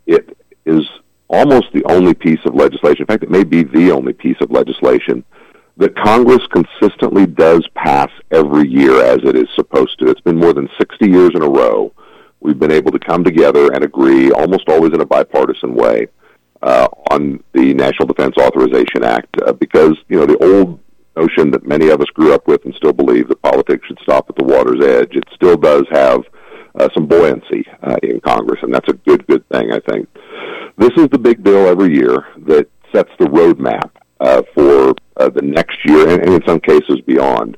Schmidt, a recent guest on KVOE’s Morning Show, says the bill is critical for setting defense policy.